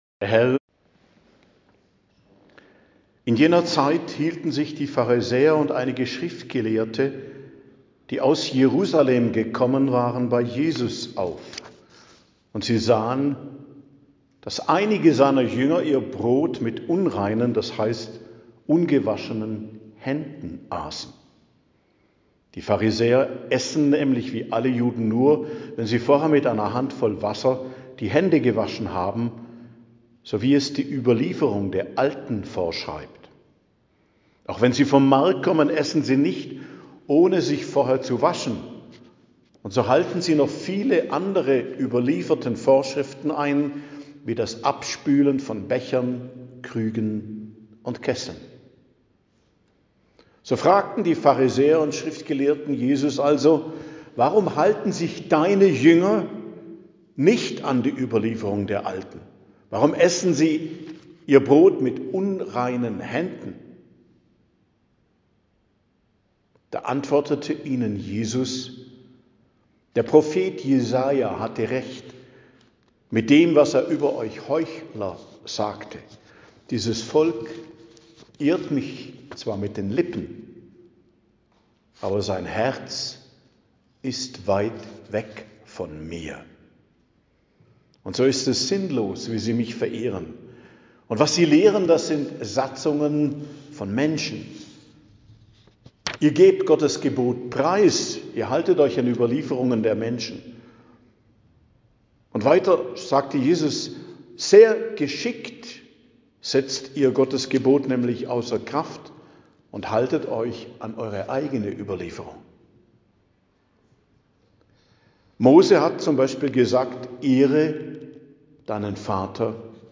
Predigt am Dienstag der 5. Woche i.J., 10.02.2026